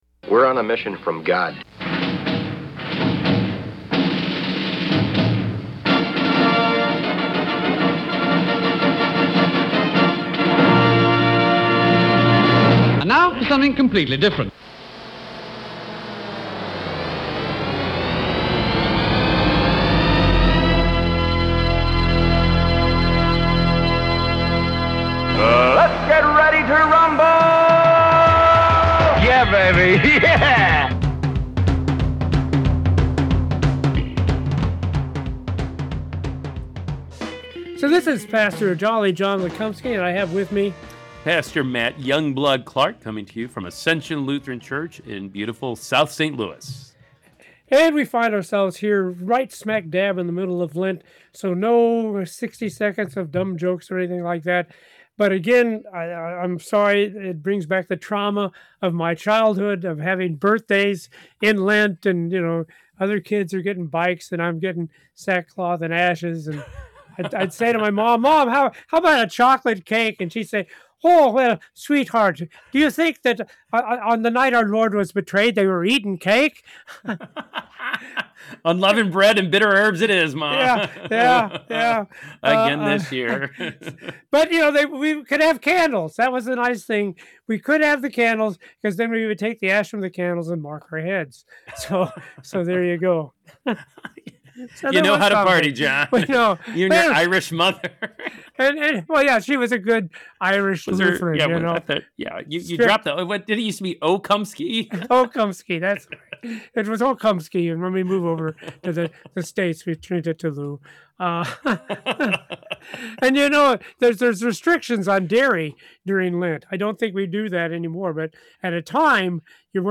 With hosts